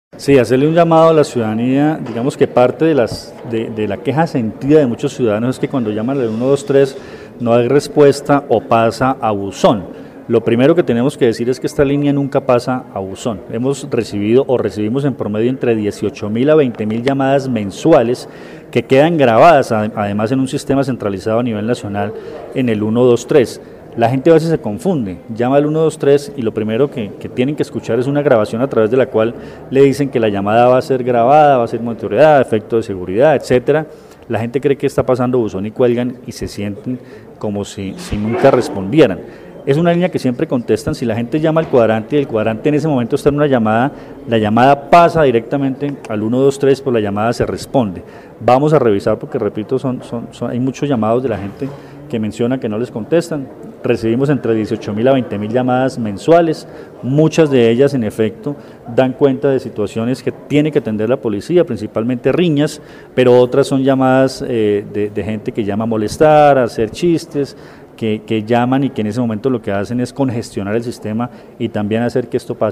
Comandante de la Policía del Quindío, Coronel Luis Fernando Atuesta